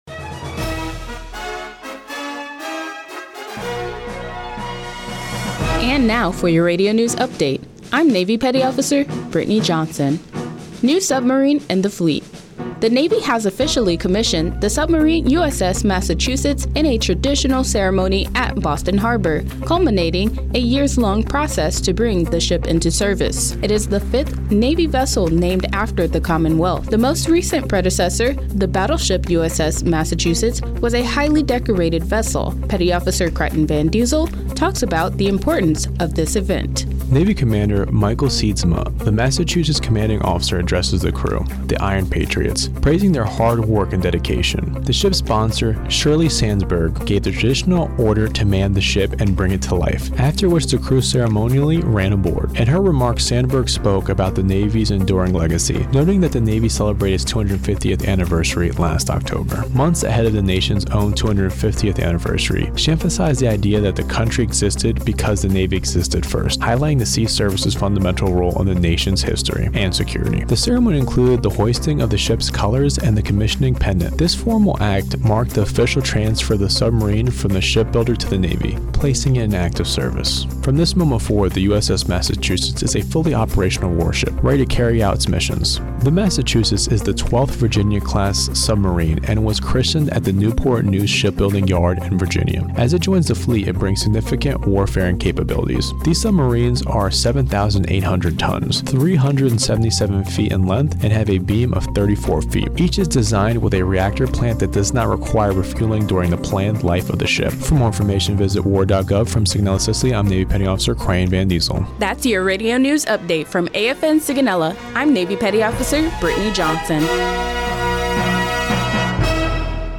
NAVAL AIR STATION SIGONELLA, Italy (March 31, 2026) Radio News highlights USS Massachusetts (SSN 798) joining the fleet. American Forces Network Sigonella is a Navy-operated American Forces Radio and Television Service station that provides host command and military information, local and world news, sports and entertainment programming for service members, their families, and DoW personnel assigned to NAS Sigonella.